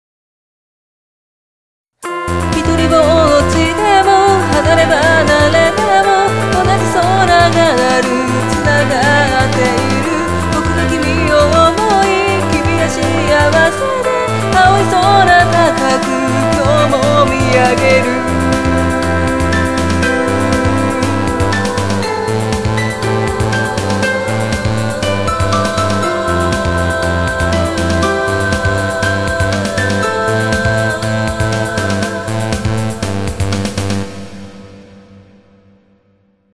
※サビだけでいきなり始まるので注意。
ギターの音が残念なんだよなぁ
音の薄さを誤魔化すためにコーラスが上下で最大８入っています。